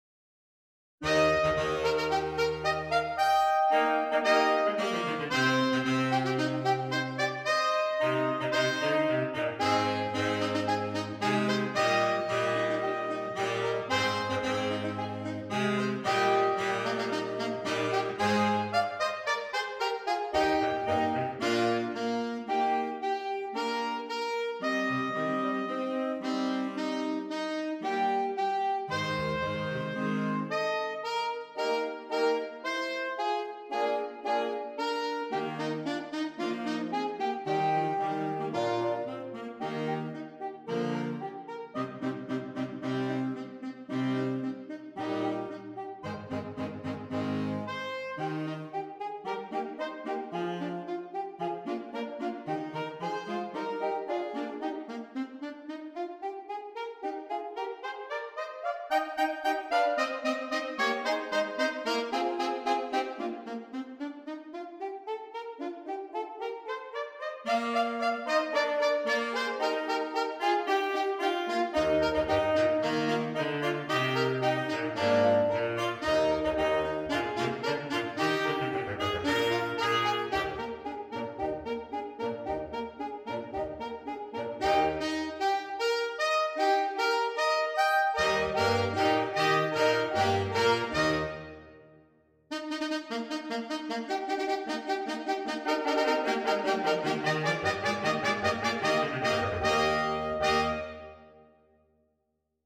Saxophone Quartet (AATB)